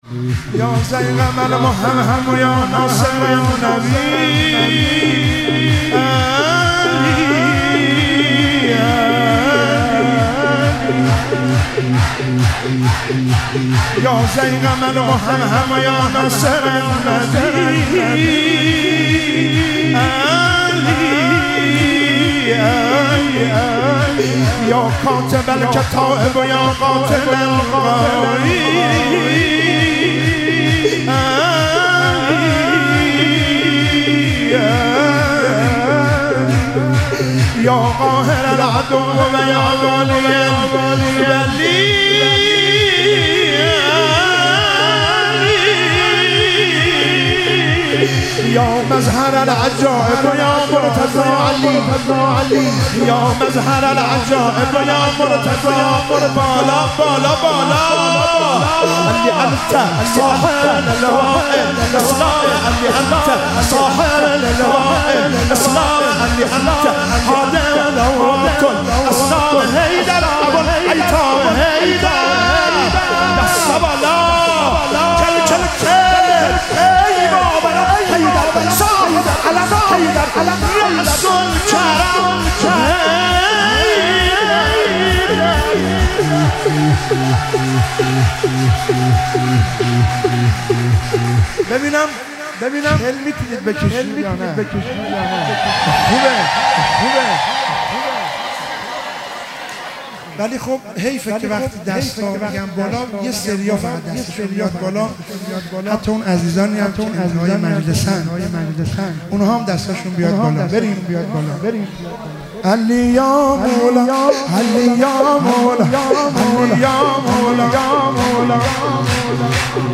مراسم مناجات خوانی شب شانزدهم و جشن ولادت امام حسن مجتبی علیه السلام ماه رمضان 1444
شور- یاضیغم المهمهم یا ناصر النبی